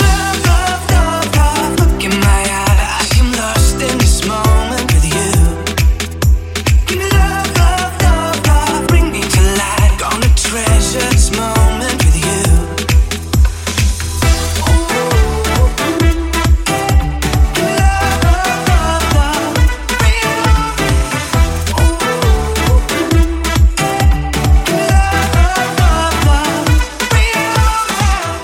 house
Genere: house, deep house, remix